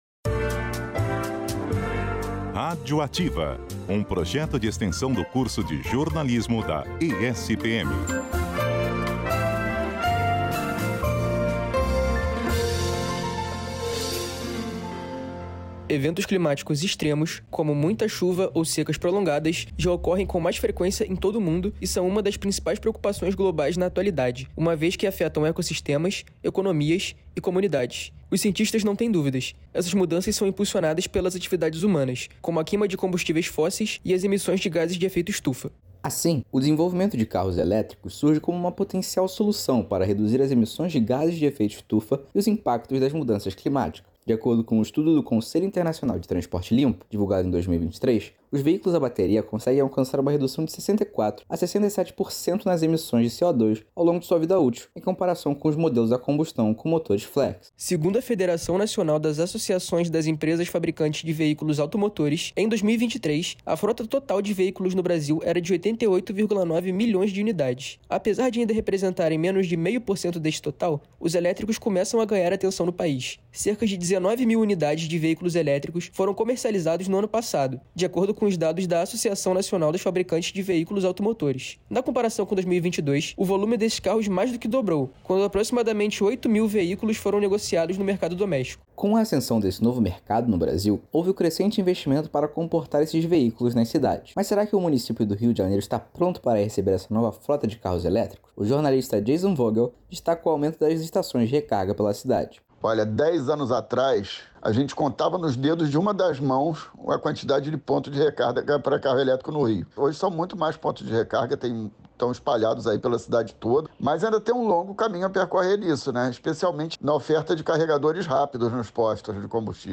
Trata-se de um projeto de extensão que reúne docentes do PPGECEI, da Graduação em Jornalismo da ESPM Rio e discentes na produção mensal de uma reportagem, com finalidade de divulgação científica.